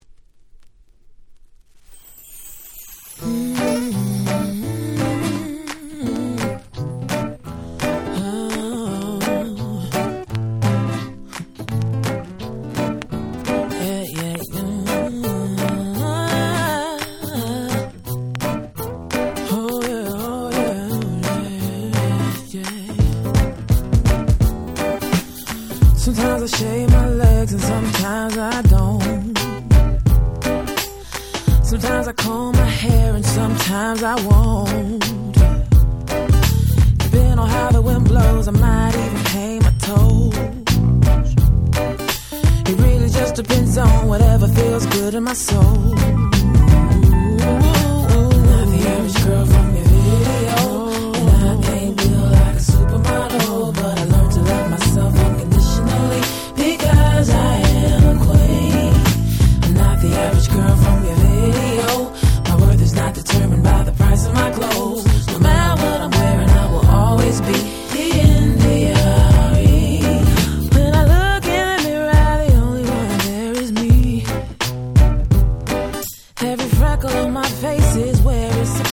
01' Smash Hit R&B / Neo Soul !!
ネオソウル